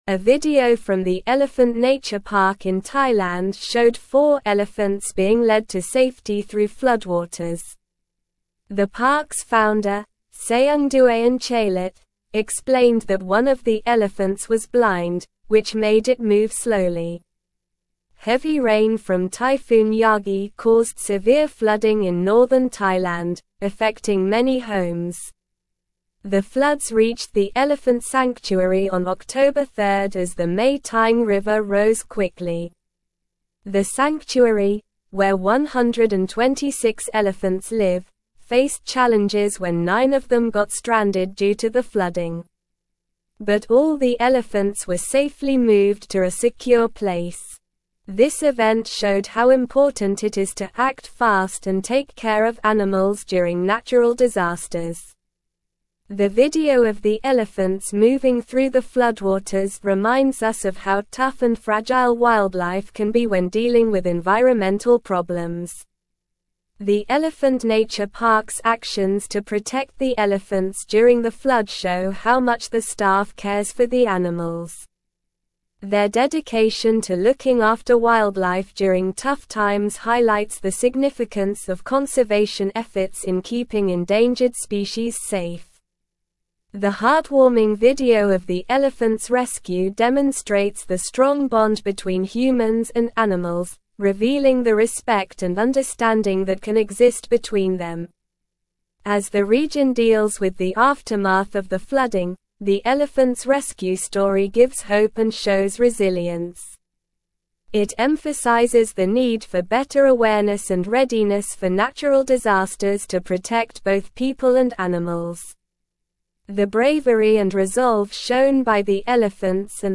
Slow
English-Newsroom-Upper-Intermediate-SLOW-Reading-Elephants-rescued-from-floodwaters-in-Thailand-sanctuary.mp3